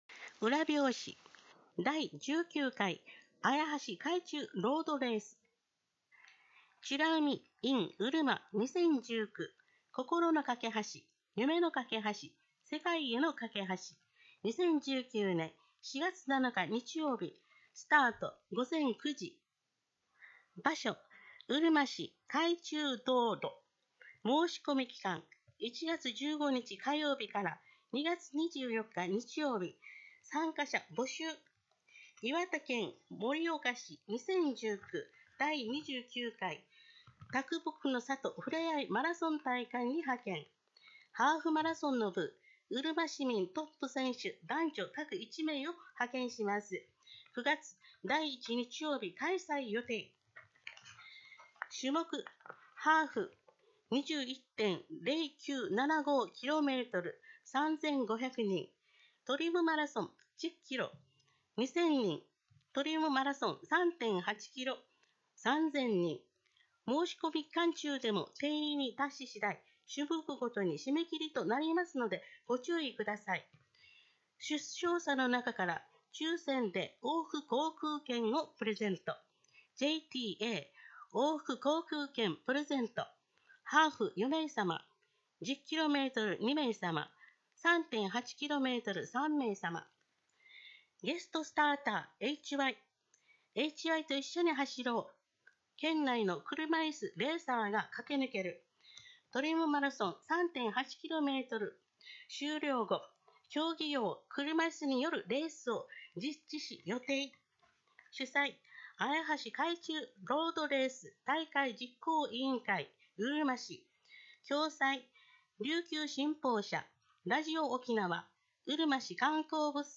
声の広報は、視覚障害をお持ちの方のため、毎月発行している「広報うるま」を音声データとしてお届けします。
音声化については、うるま市社会福祉協議会で活動している「朗読サークル すだち」のご協力で行っております。